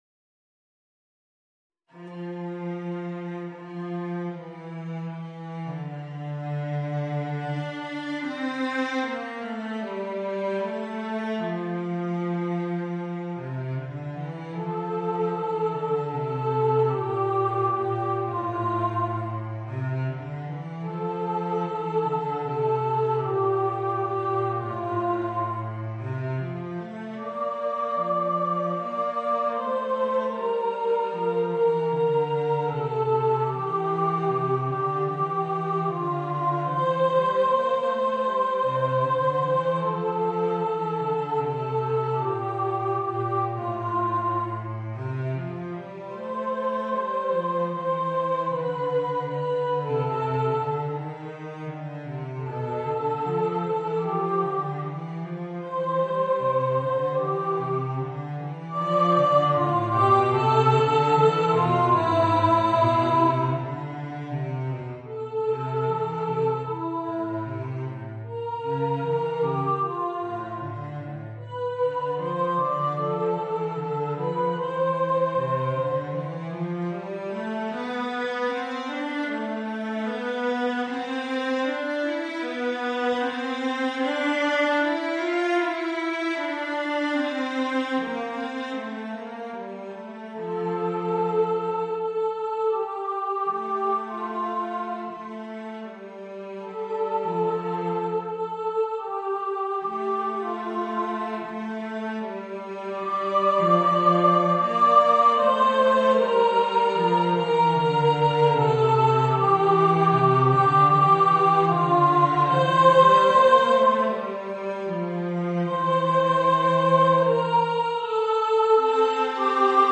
Voicing: Violoncello and Voice